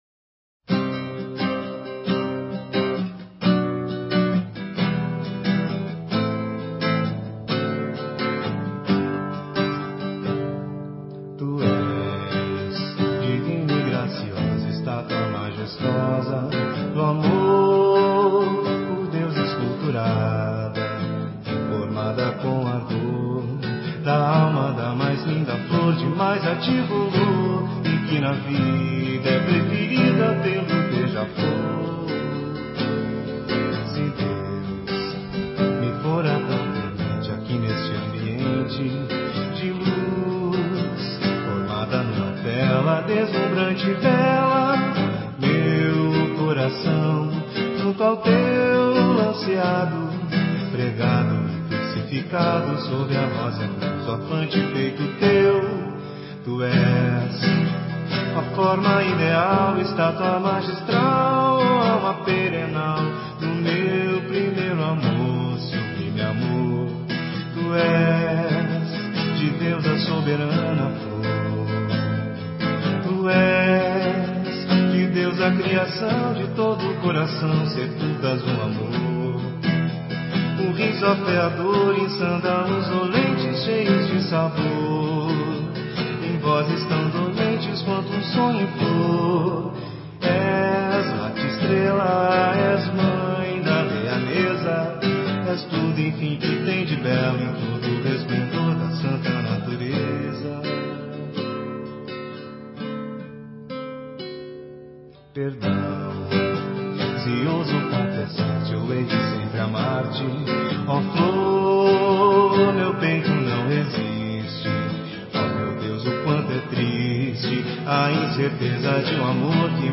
MPB